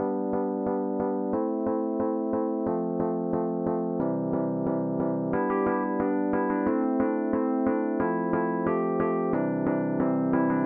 嘻哈和洛菲的风琴钢琴或罗兹舞曲
描述：嘻哈和洛菲音乐的风琴钢琴或罗兹舞曲
Tag: 90 bpm Hip Hop Loops Piano Loops 1.79 MB wav Key : Unknown